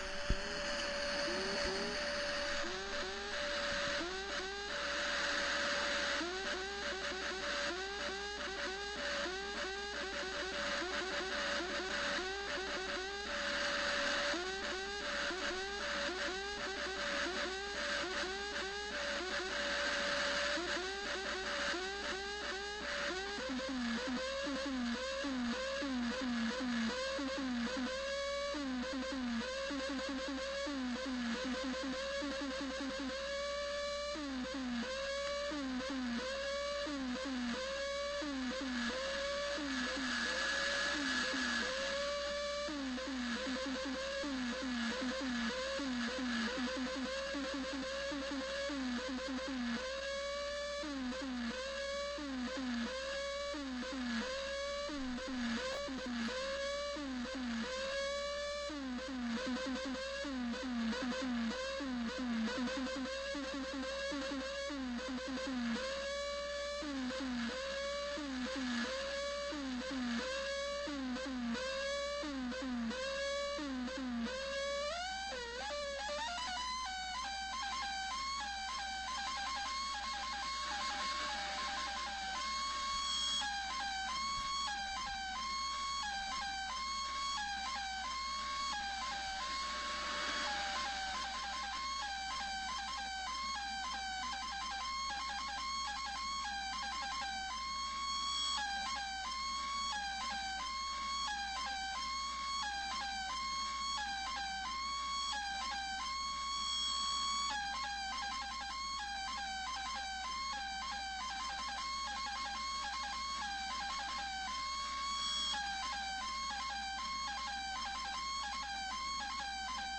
50 MHz DX SOUND FILES
The recordings themselves were made with an old cassette tape recorder located below one of the two outdated Labtec sound-blaster speakers I use as externals.